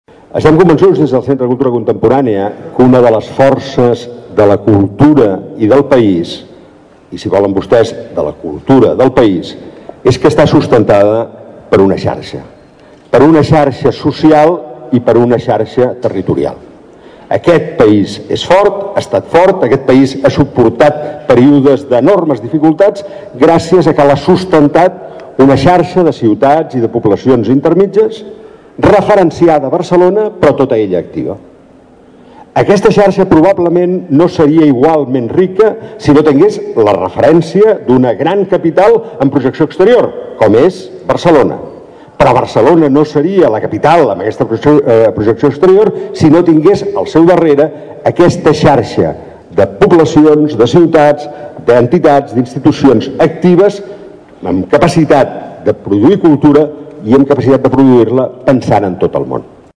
El director del Centre de Cultura Contemporània de Barcelona, Vicens Villatoro, destacava el format del projecte de “Ciutat Subterrània” i l’impuls del territori en la cultura del país.